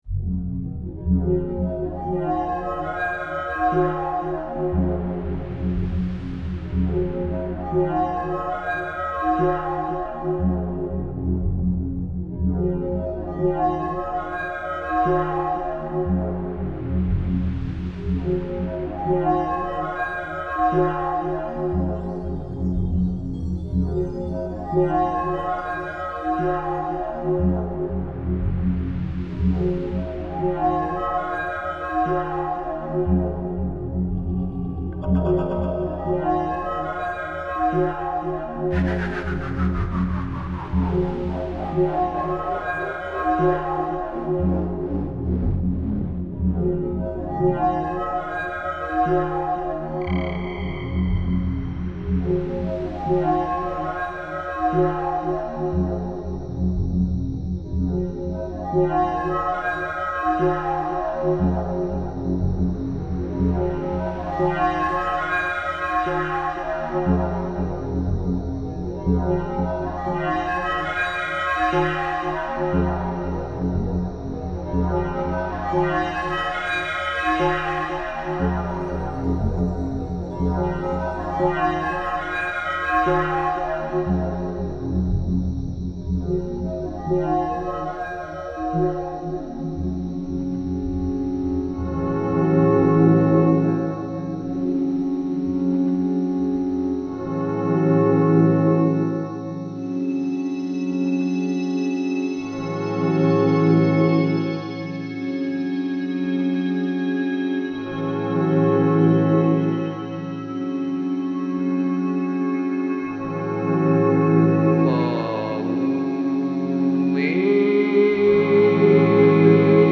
Genre: Deep Ambient.